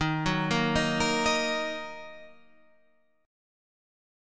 D# Minor